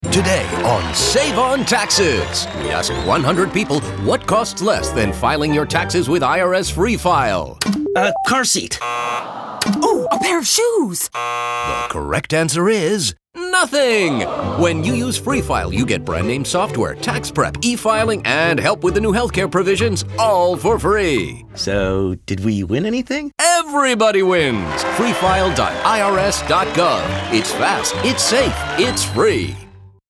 2014-12-18 00:00:00 1.15 MB IRS Free File - Game Show, The Next Round - Radio PSA :30